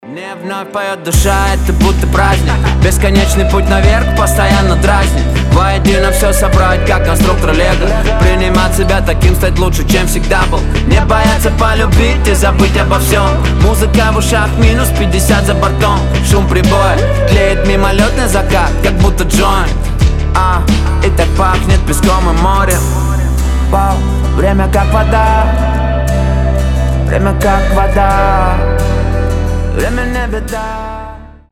• Качество: 320, Stereo
гитара
позитивные
душевные
красивый мужской голос
русский рэп
мотивирующие